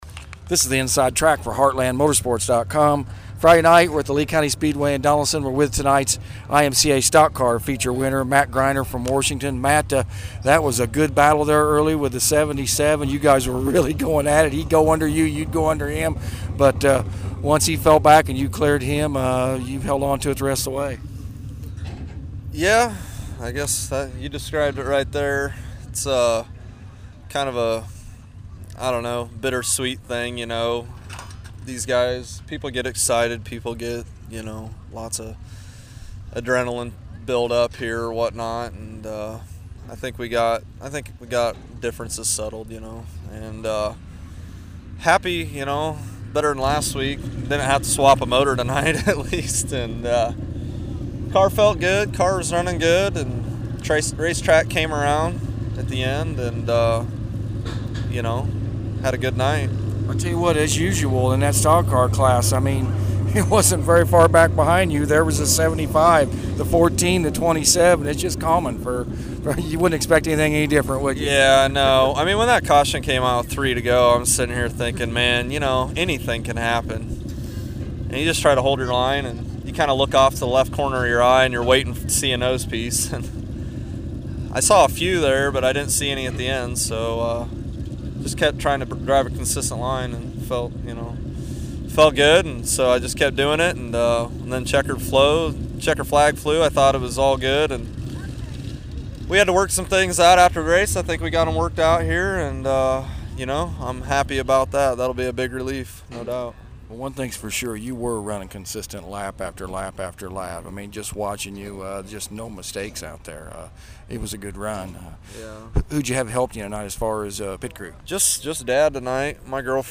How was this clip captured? Lee County Speedway Interviews from May 11, 2012